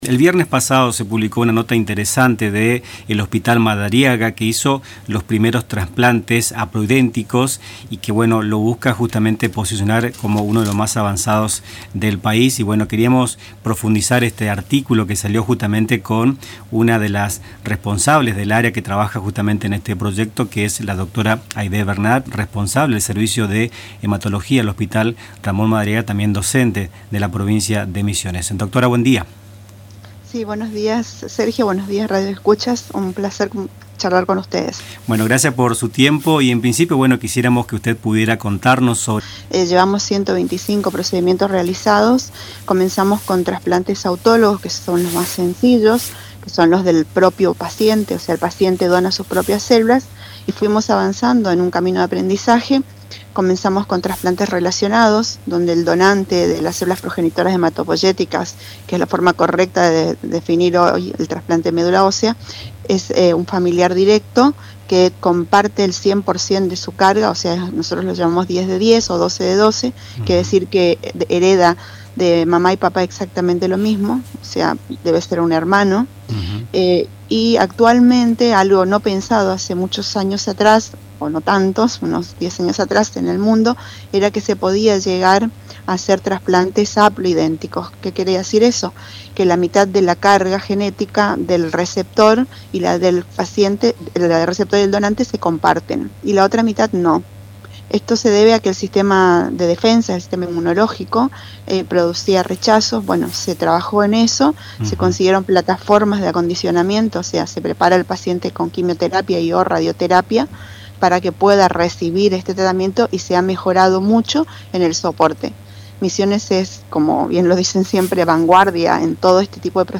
En “Nuestras Mañanas”, entrevistamos